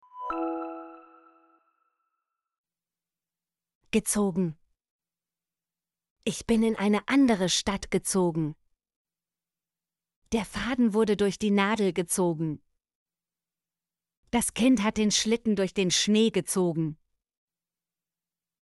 gezogen - Example Sentences & Pronunciation, German Frequency List